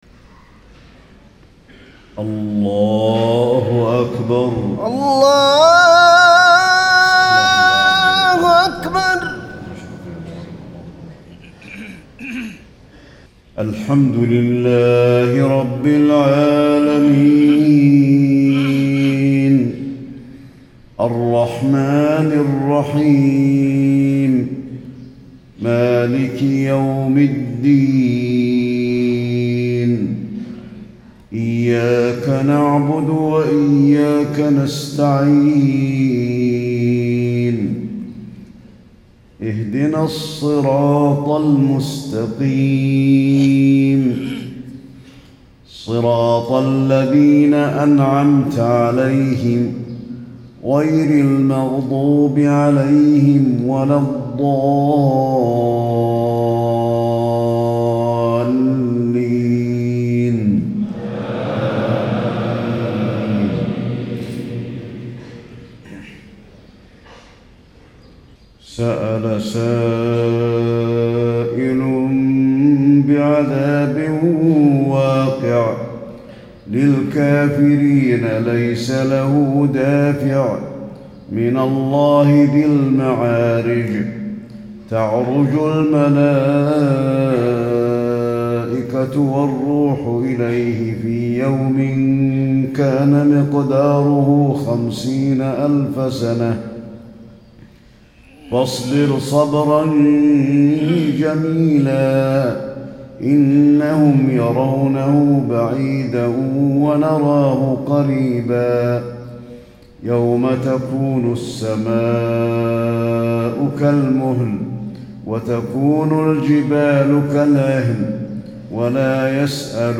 صلاة الفجر 1 - 3 - 1436 تلاوة من سورة المعارج .